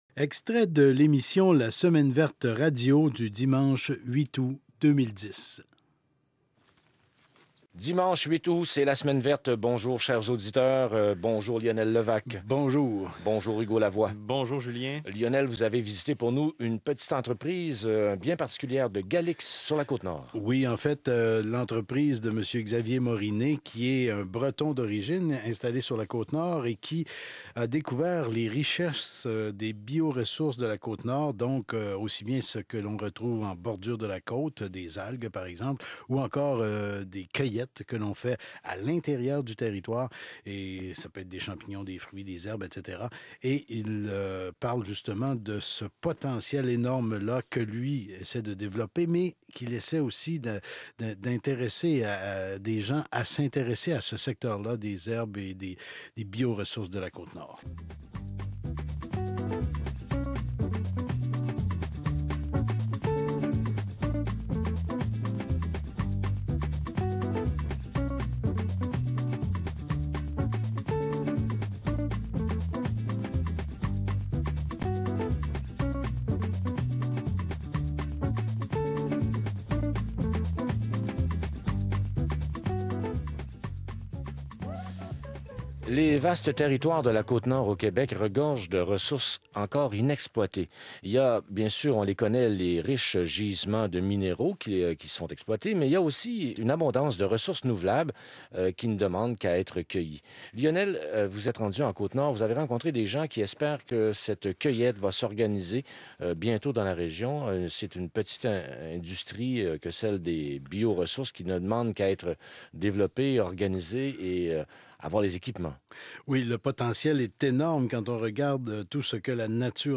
La Semaine Verte» : visite guidée au sein de l’entreprise Trésors des Bois.